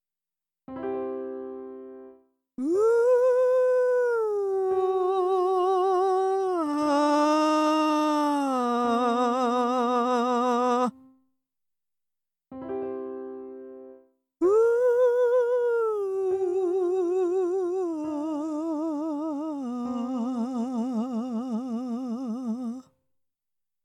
参考音源：失敗パターン
音量注意！
音源冒頭は完全に地声に切り替わっている・断絶してしまっているという失敗パターンで、後半は地声の要素があまりにも少なすぎて、ほぼ裏声の下降になってしまっているという失敗パターンです。